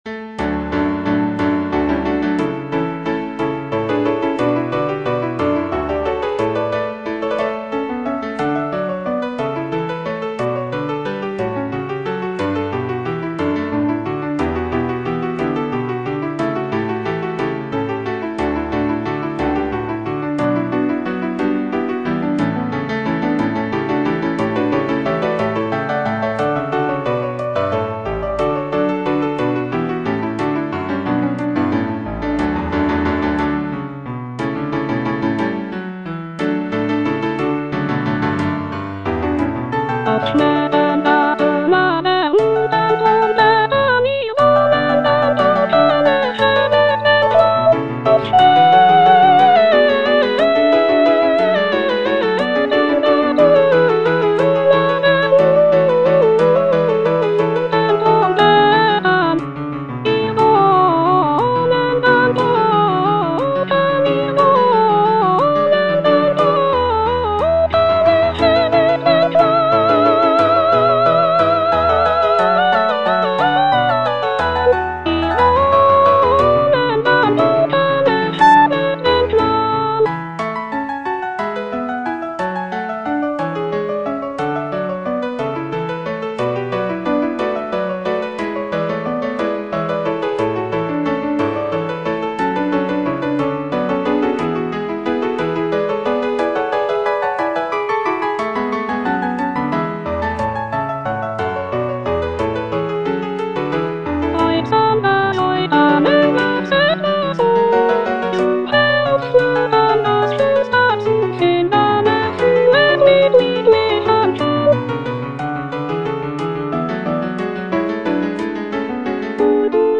Cantata
Soprano (Voice with metronome) Ads stop